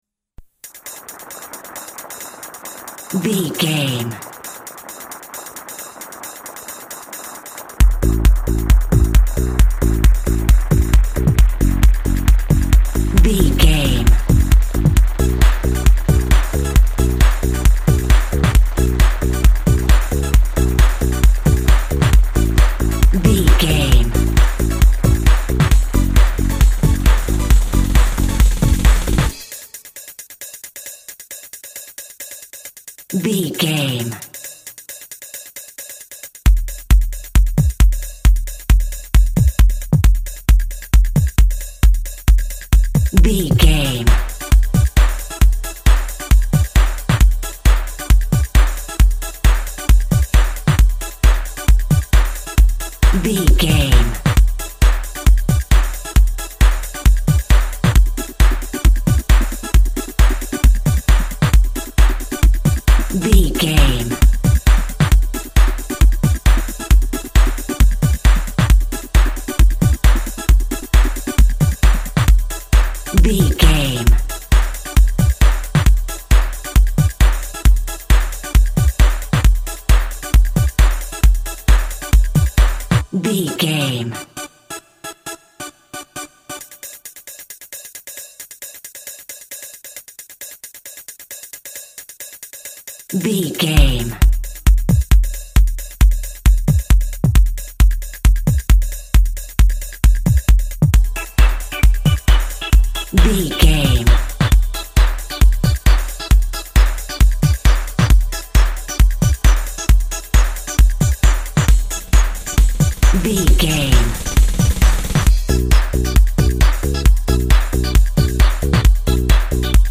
Ionian/Major
D
groovy
uplifting
driving
energetic
repetitive
bass guitar
drums
electric organ
electric piano
synthesiser
house music
dance music
electronic
synth lead
synth bass
electronic drums
Synth Pads